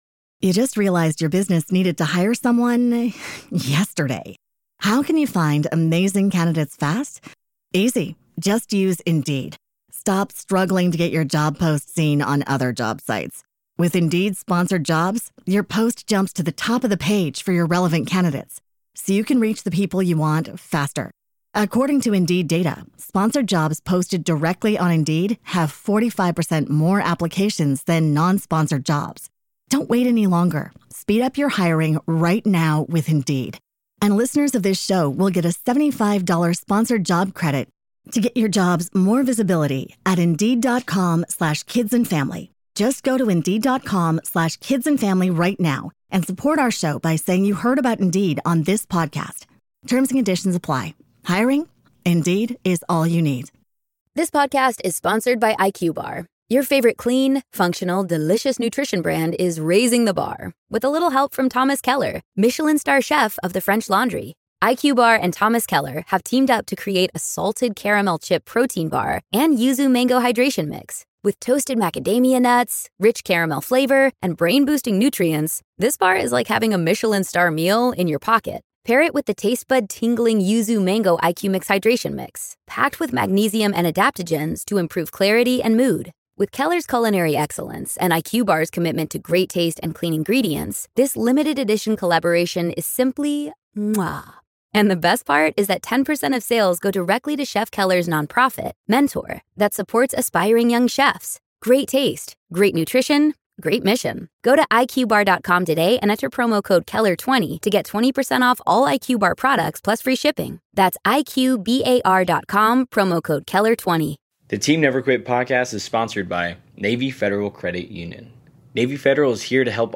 Note: Not all families were able to participate in the video calls from which this audio was derived.